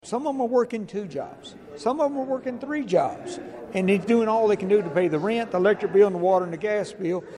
The Human Rights Commission of Hopkinsville–Christian County hosted its second public forum on the Uniform Residential Landlord–Tenant Act.